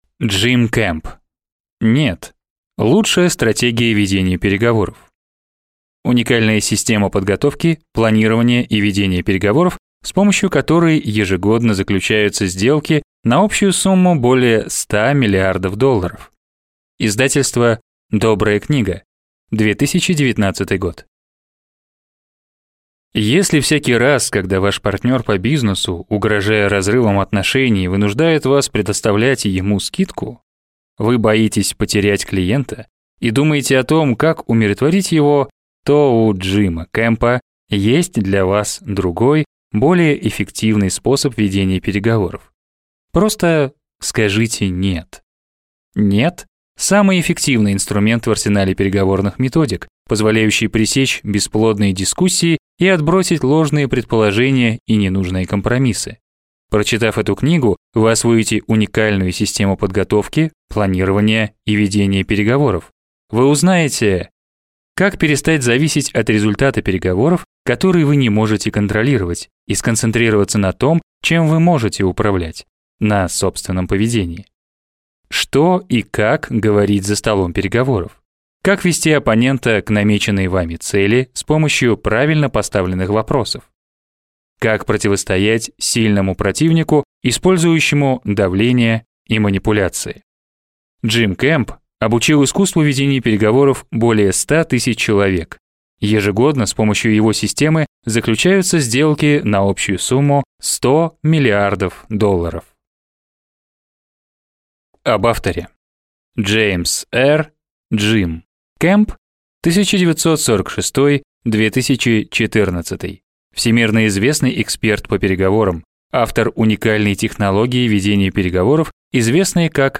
Аудиокнига «Нет». Лучшая стратегия ведения переговоров | Библиотека аудиокниг